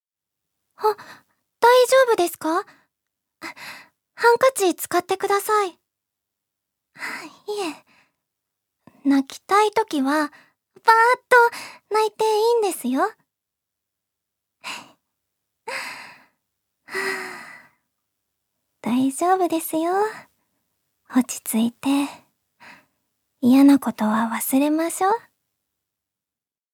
ジュニア：女性
セリフ２